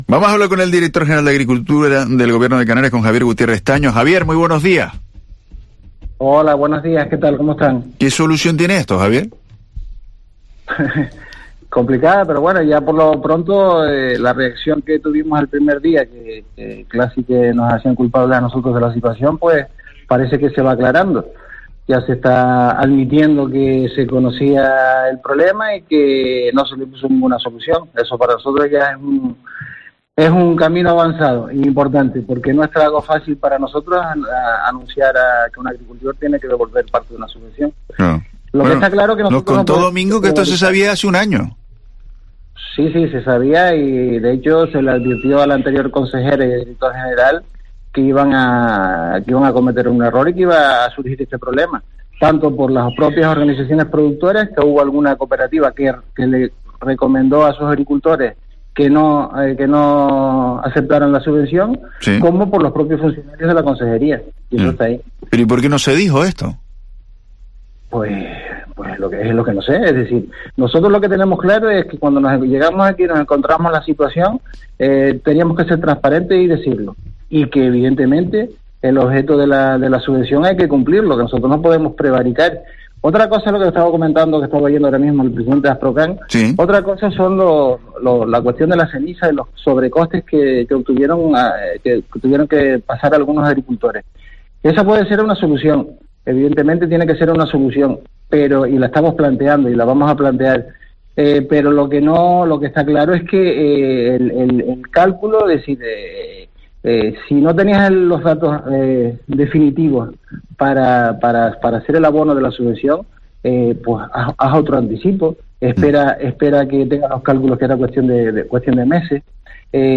El director general de Agricultura, Javier Gutiérrez, explica las devoluciones de ayudas de La Palma